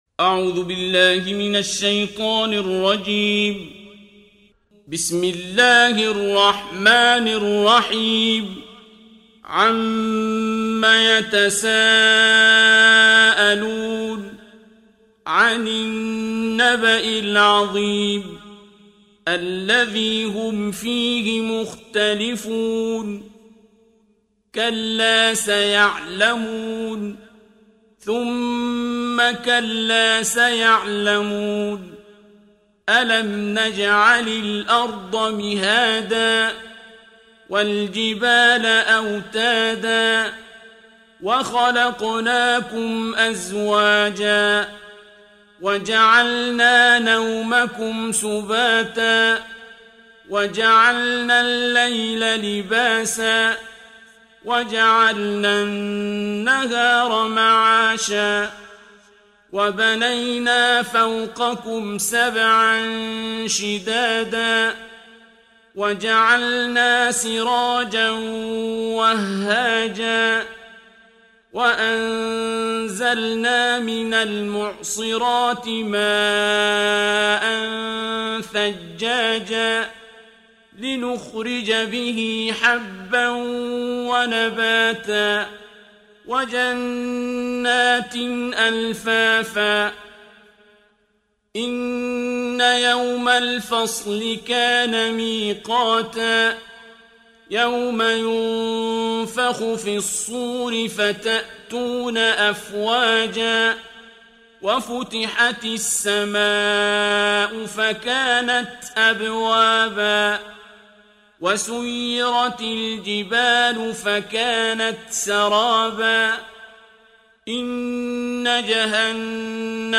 Коран mp3 - сборник чтений Священного Корана - 'Абдуль-Басит 'Абдуль-Самад * – القارئ عبد الباسط عبد الصمد